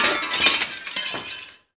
Index of /90_sSampleCDs/Roland L-CD701/PRC_Guns & Glass/PRC_Glass Tuned
PRC GLASS0HR.wav